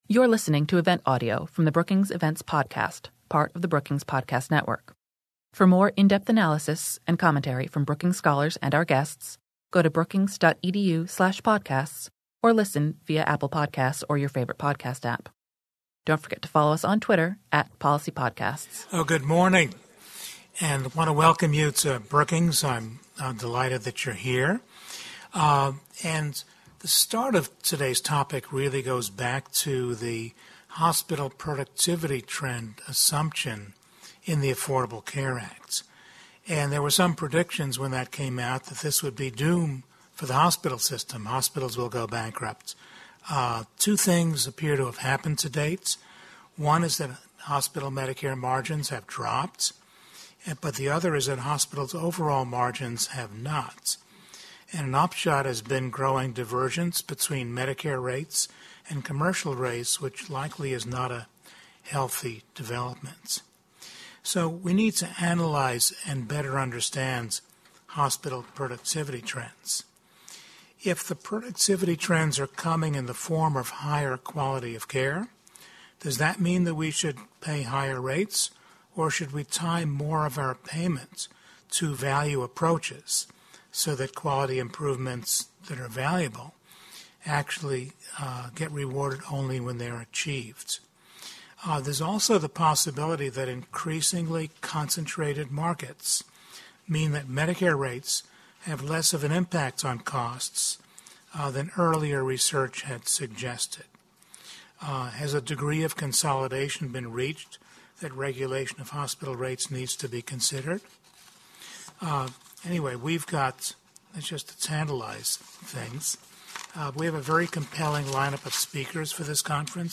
On June 25, 2019, the USC-Brookings Schaeffer Initiative for Health Policy hosted a conference on hospital productivity trends and their implications for Medicare policy on hospital payment rate updates. The event consisted of a presentation on the established literature on hospital productivity, followed by two expert reactions, and a panel to discuss the policy implications of these trends and possible changes in policy.